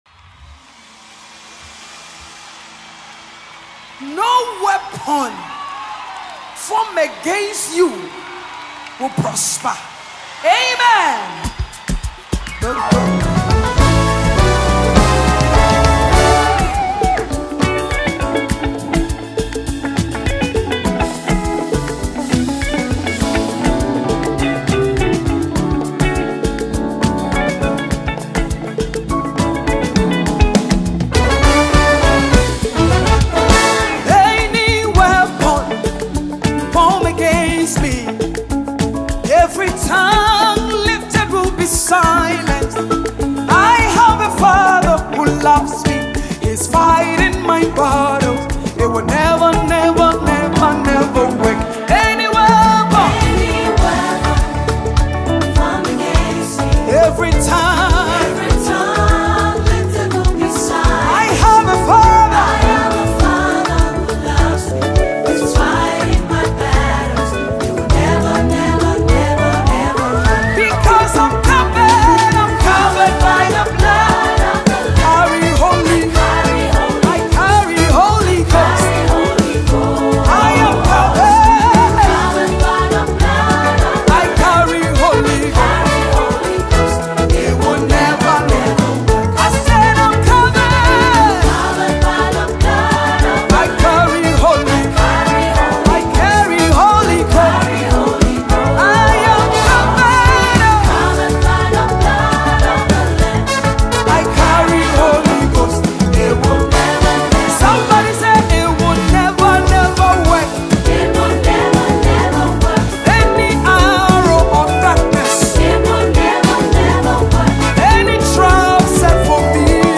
Gospel
spirit-filled song
With her soulful voice and heartfelt delivery
prophetic anthem